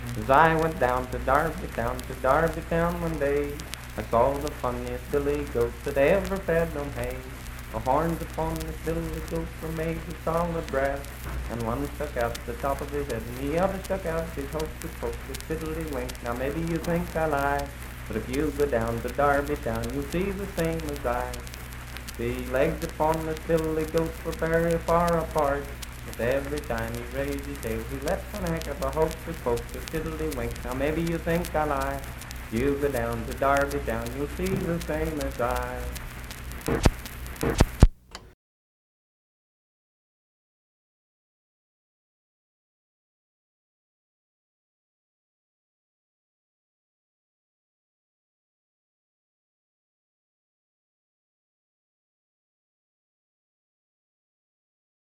Unaccompanied vocal performance
Voice (sung)